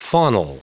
Prononciation du mot faunal en anglais (fichier audio)
Prononciation du mot : faunal